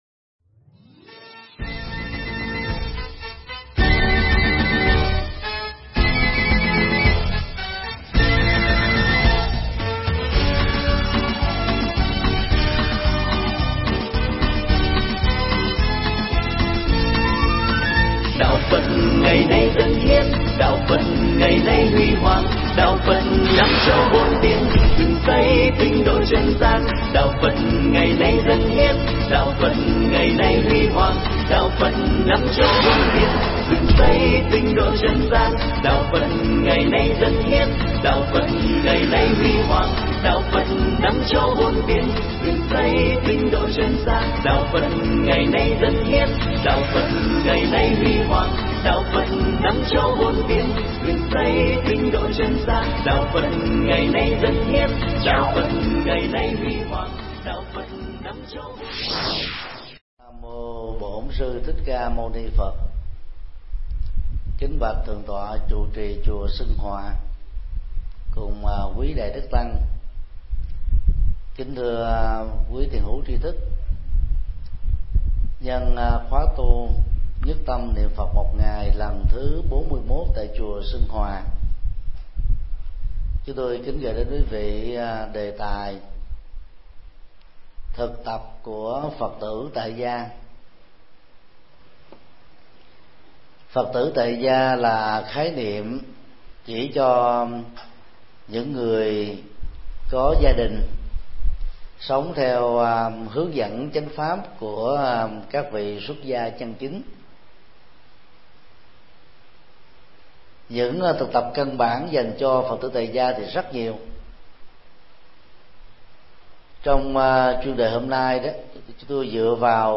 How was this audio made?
Giảng tại chùa Sùng Hòa, Đồng Nai